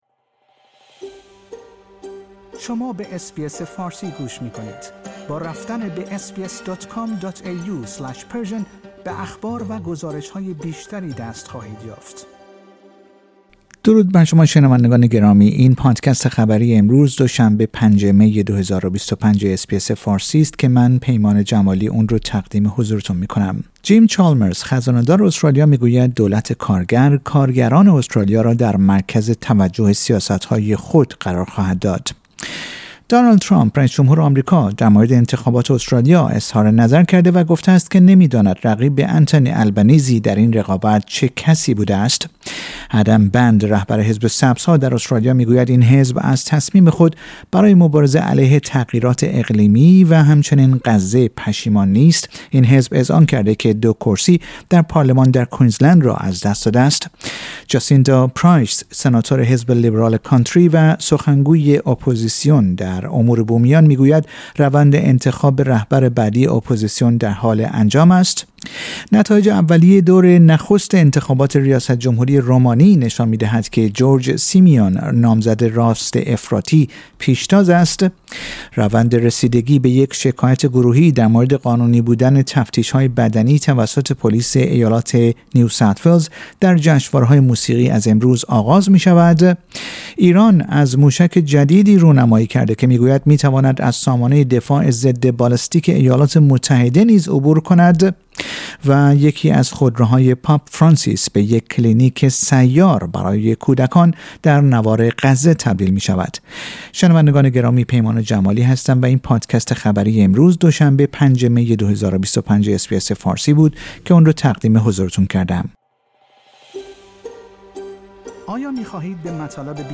در این پادکست خبری مهمترین اخبار امروز دو شنبه پنجم مه ارائه شده است.